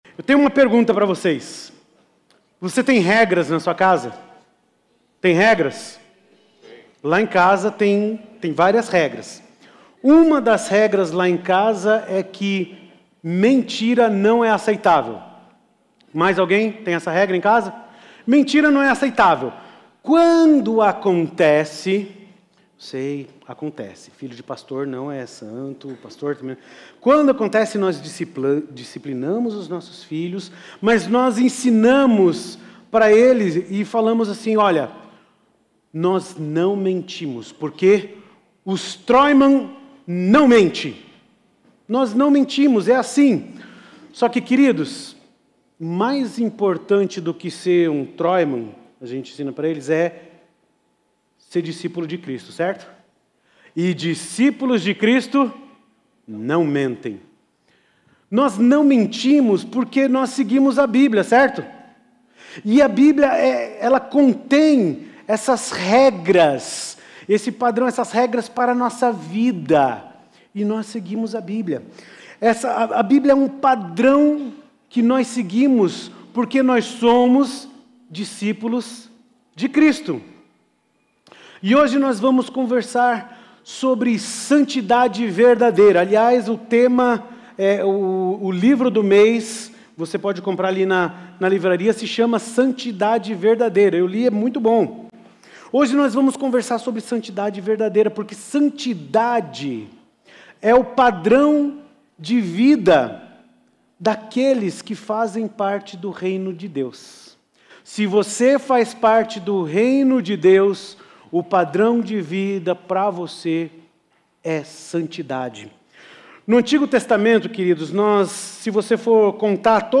Mensagem
na Igreja Batista do Bacacheri.